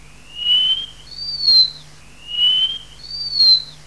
Picchio muraiolo
• (Tichodroma muraria)
Segnalato nei dintorni di Nibbio e sul monte Torrione
picchio_muraiolo.wav